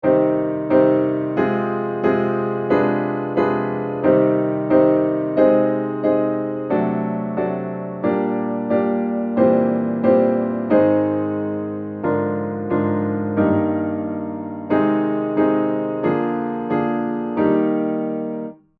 (Die Tonart ist übrigens C-moll (Paralleltonart von Es-Dur) ).
Um die Harmonien besser zu verstehen, kann man weite Teile des Präludiums als Akkorde spielen (die oben genannten Zwischentöne weglassen, lässt sich sonst auch gar nicht spielen).